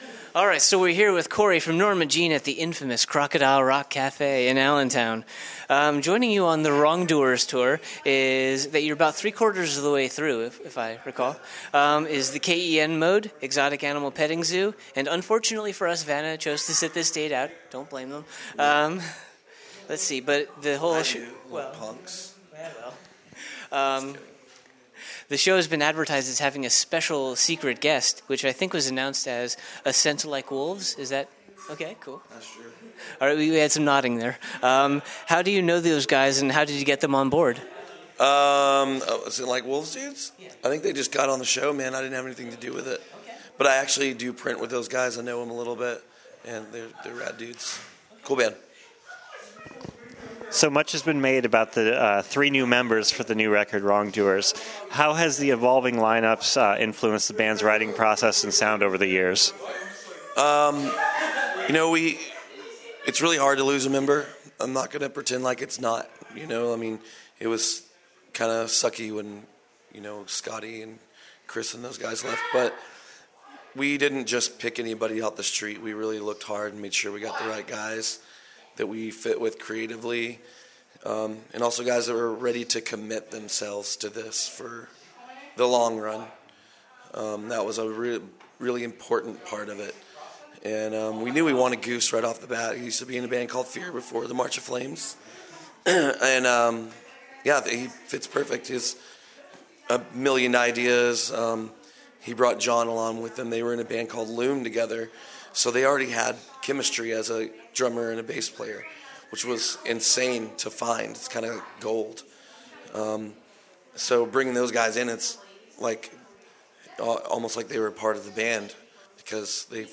Exclusive: Norma Jean Interview
41-interview-norma-jean.mp3